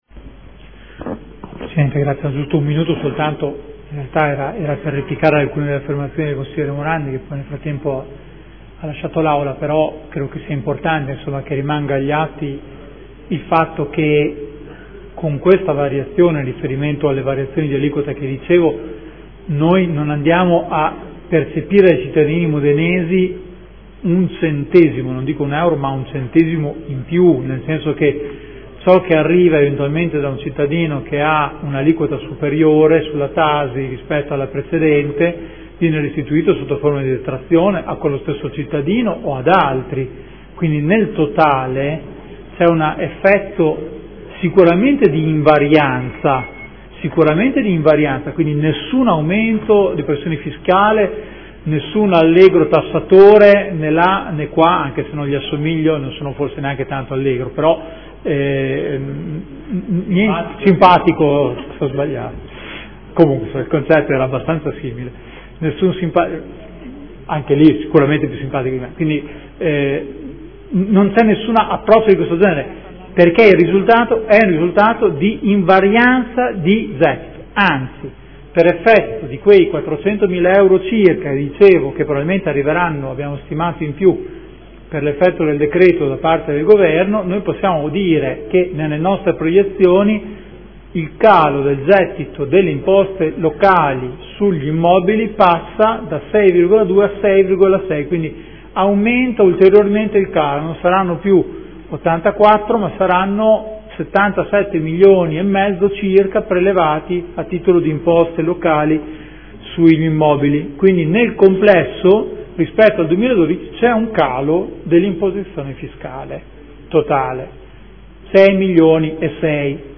Seduta del 28/04/2014. Replica.